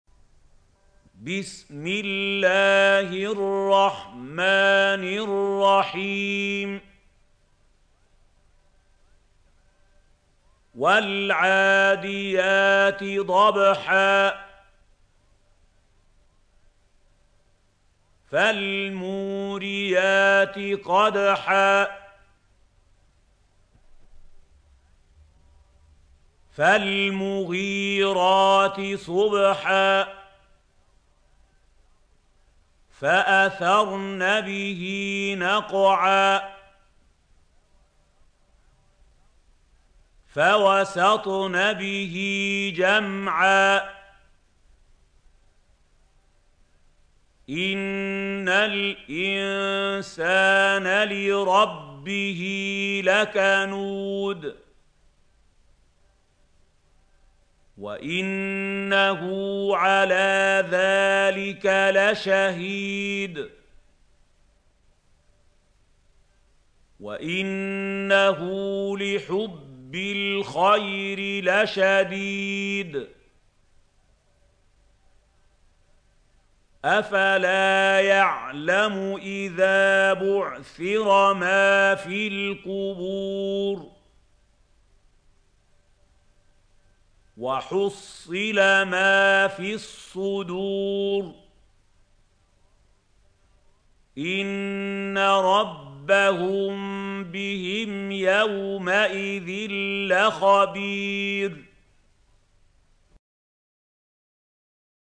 سورة العاديات | القارئ محمود خليل الحصري - المصحف المعلم